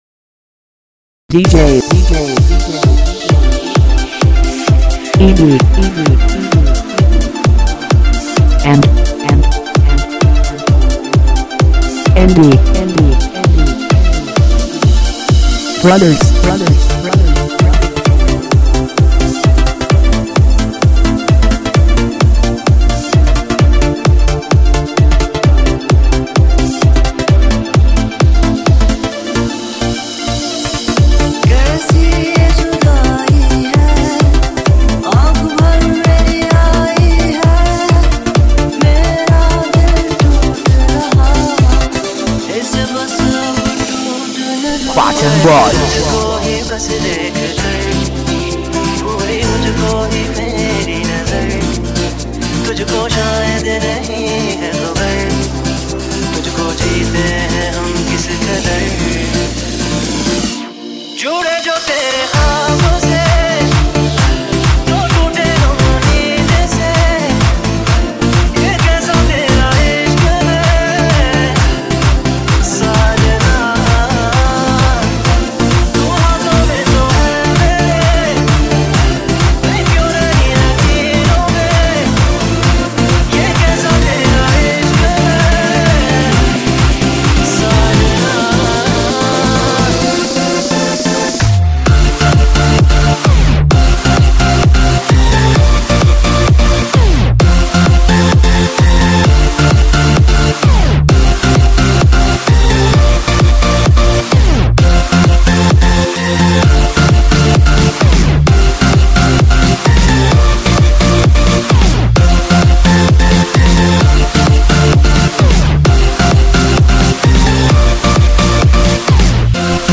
Latest DJ-Mixes